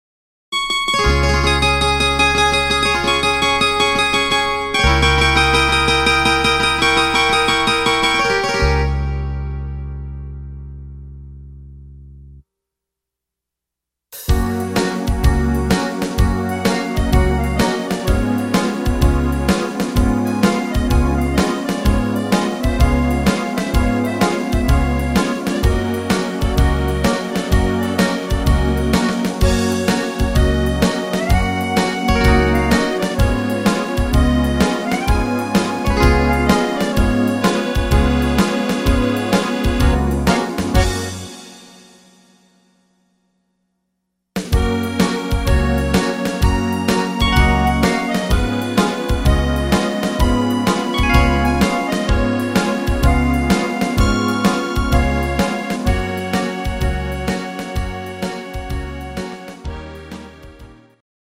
instr. Zither